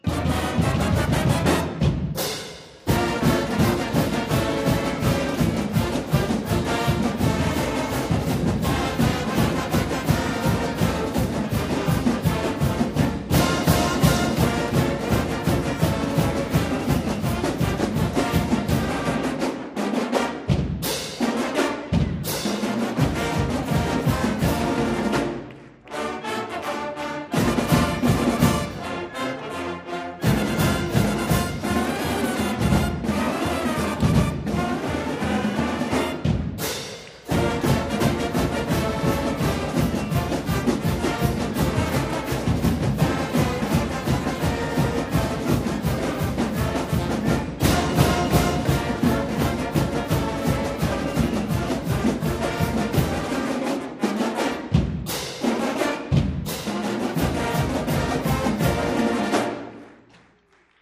GoldStarBand-FightSong.mp3